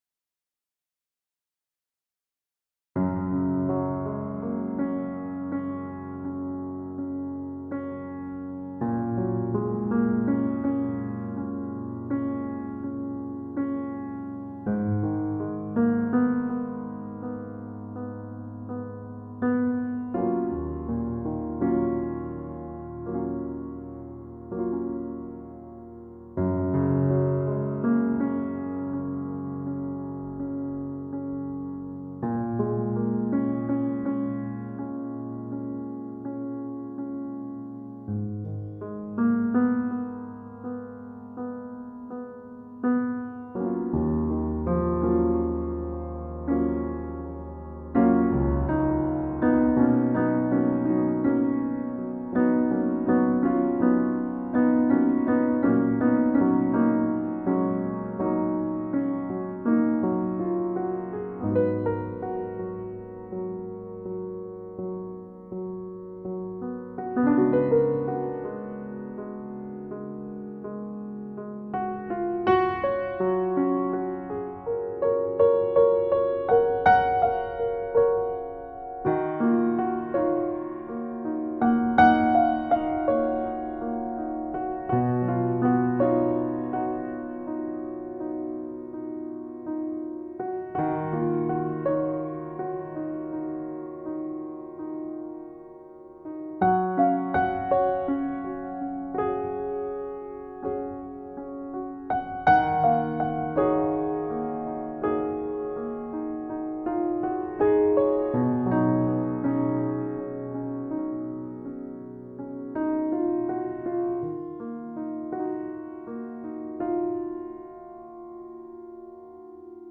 Largo [40-50] melancolie - piano - - -